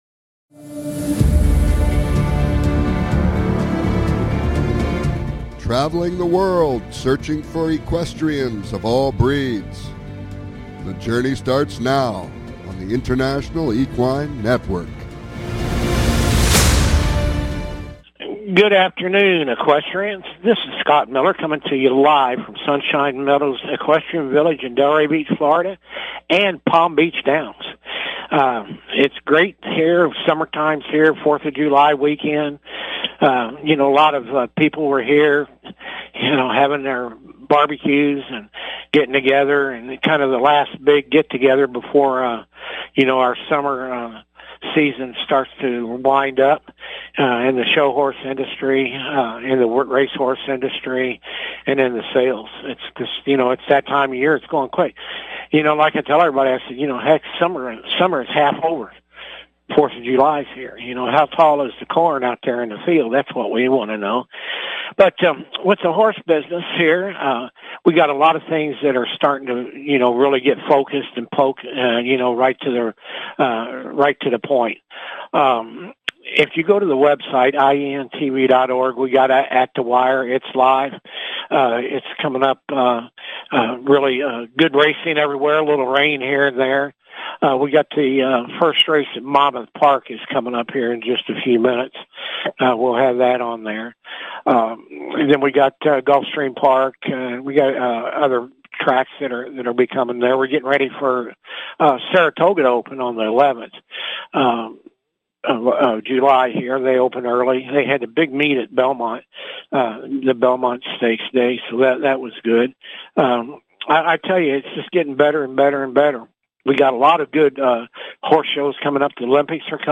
Talk Show Episode
Calls-ins are encouraged!